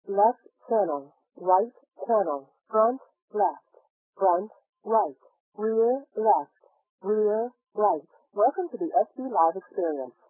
Lowering of frequencies above 1500 Hz with compression ratio=4
After compression and decompression
by the VLC 8 codec at 12250 bps